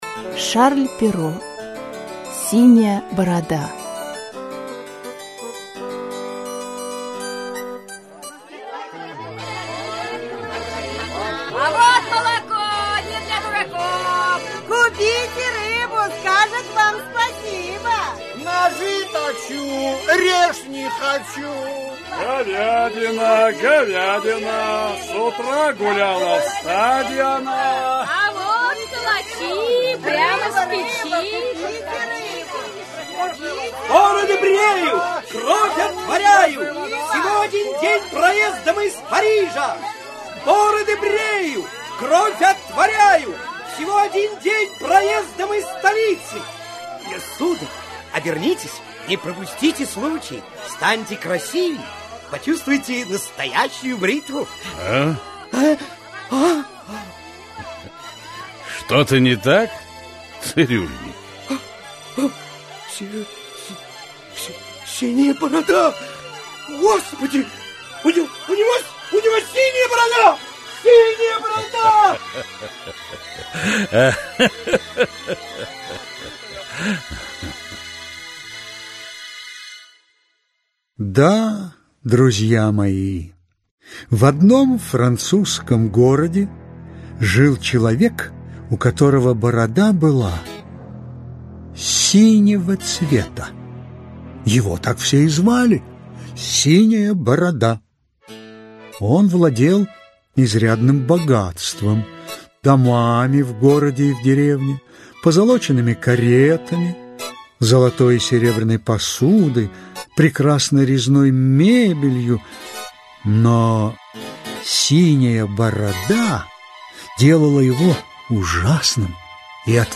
Аудиокнига Синяя Борода. Аудиоспектакль | Библиотека аудиокниг